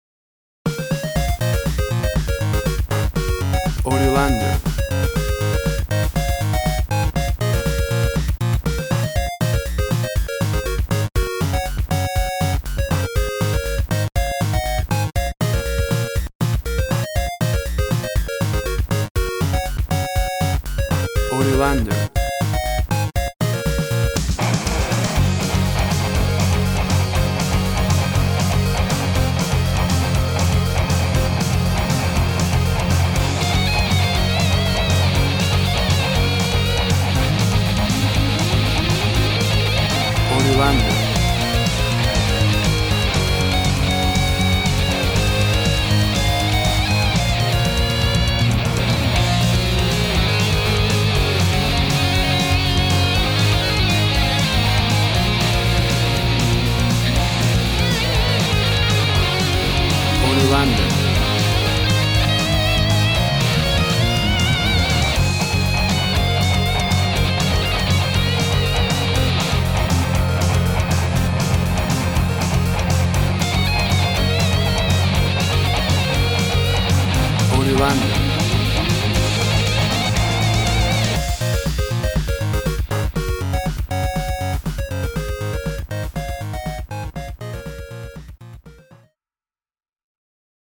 Track from game music series – incidental optimist.
WAV Sample Rate 16-Bit Stereo, 44.1 kHz
Tempo (BPM) 120